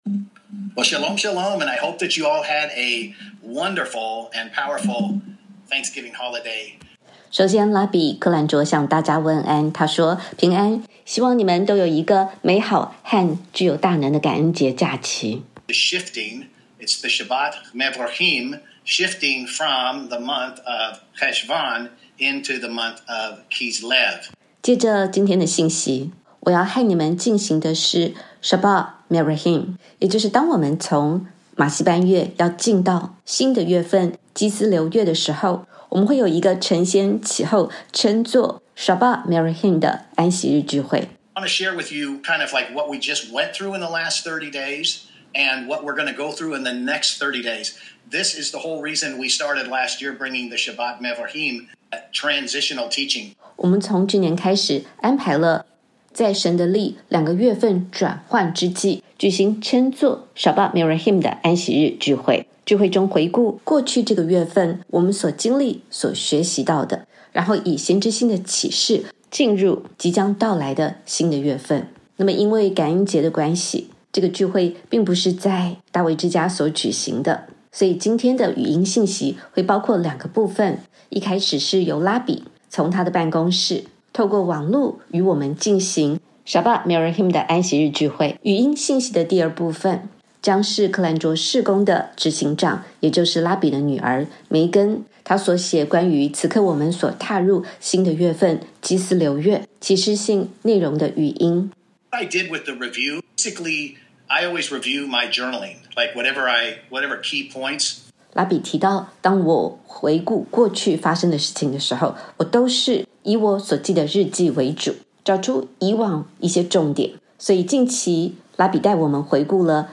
本篇感谢大卫之家 允诺口译并授权微牧刊登